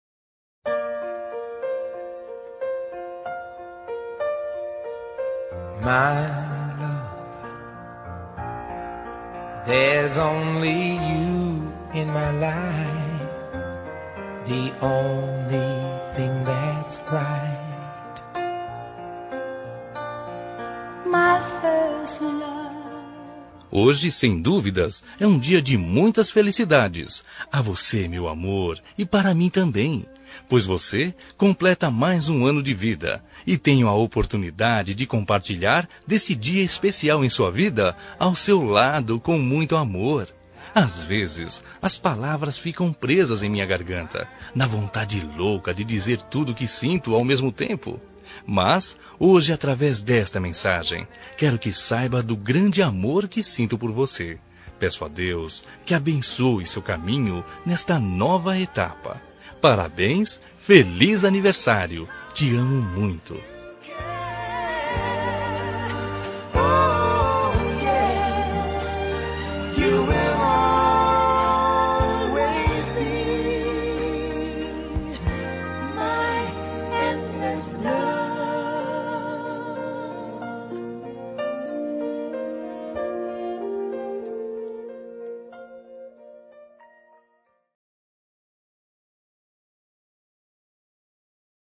Telemensagem de Aniversário Romântico – Voz Masculino – Cód: 1087